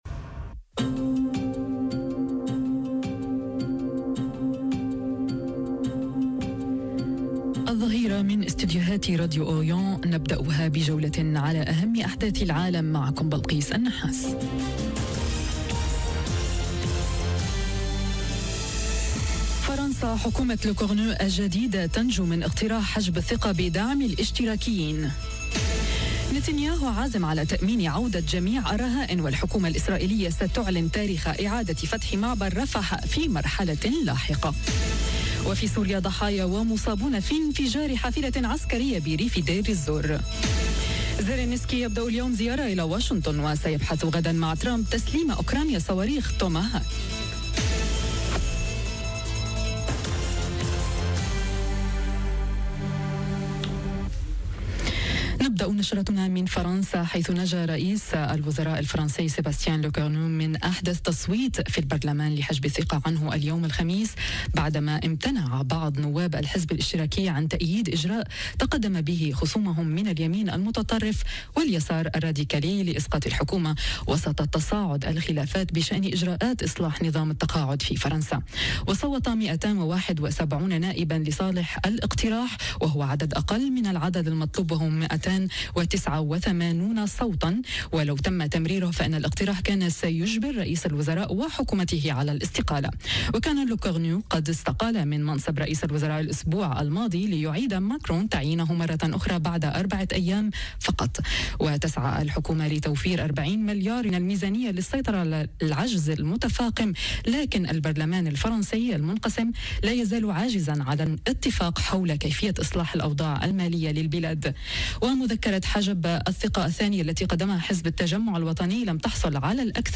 العناوين